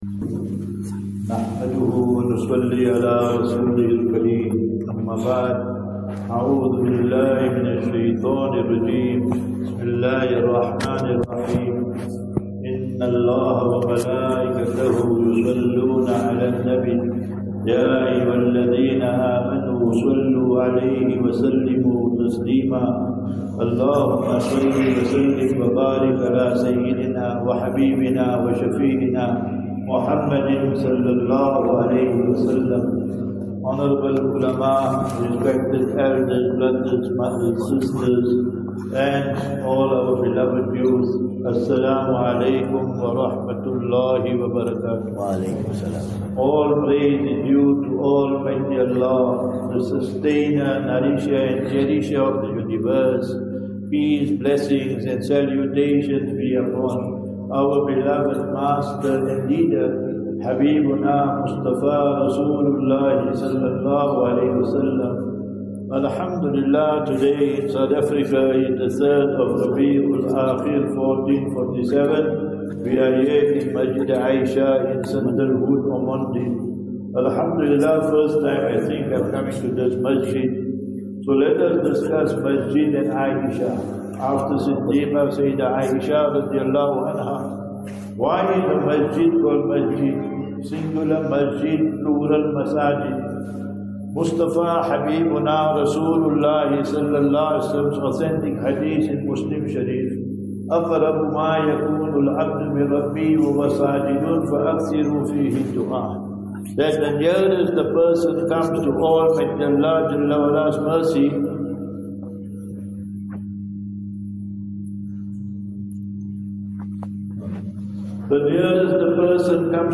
26 Sep 26 September 25 - Jumu'ah Lecture at Masjid A'isha (Ormonde)